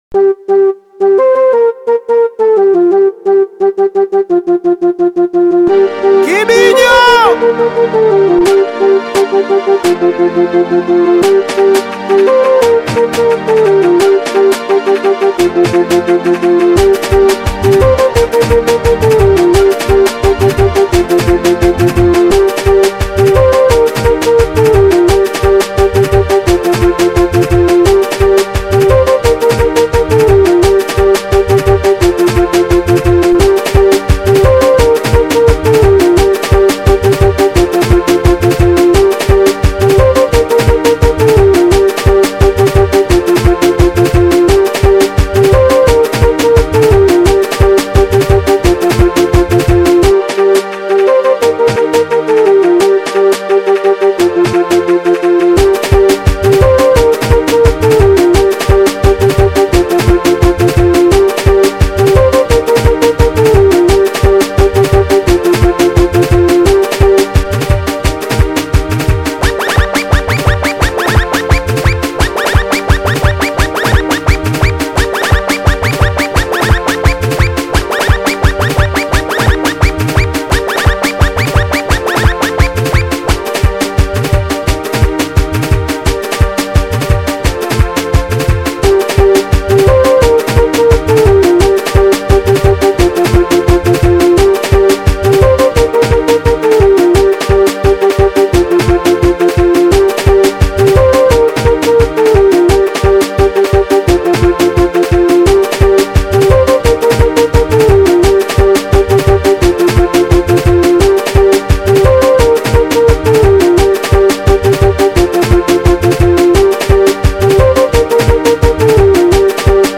DOWNLOAD BEAT SINGELI
BEAT ZA SINGELI